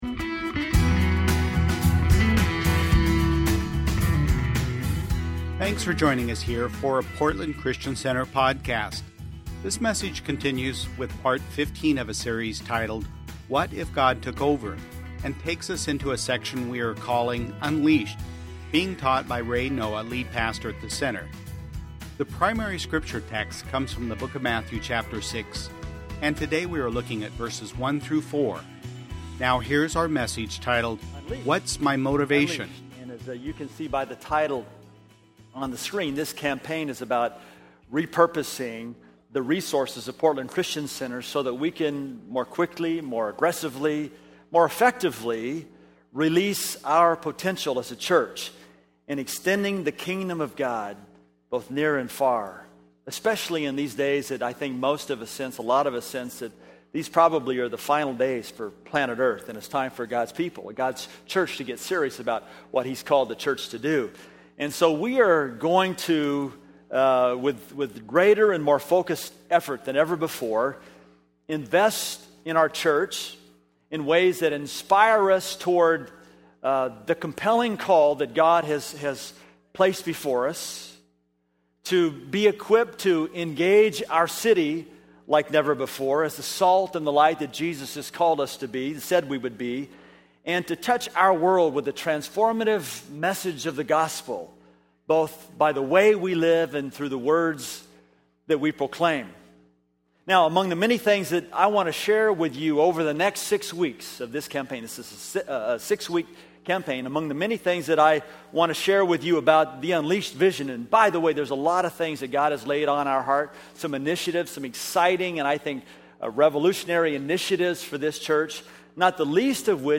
Sunday Messages from Portland Christian Center What If God Took Over?